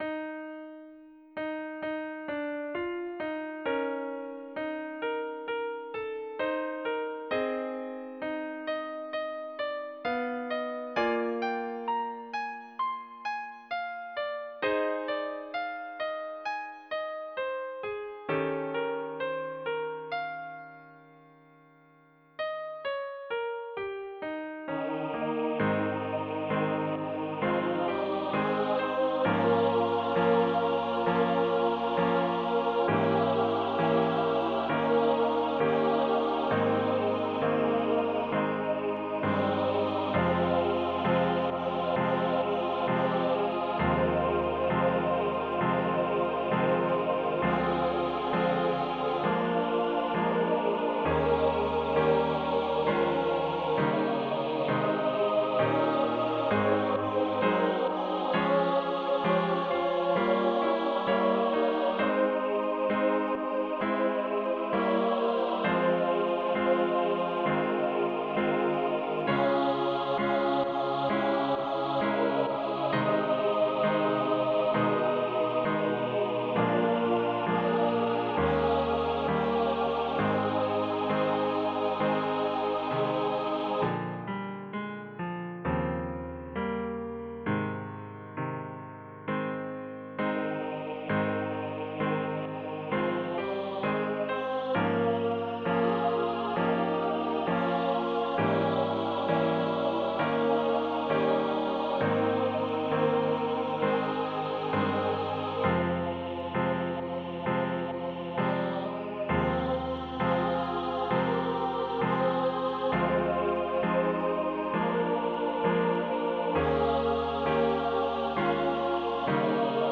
Anthem for SATB choir and piano.
Voicing/Instrumentation: SATB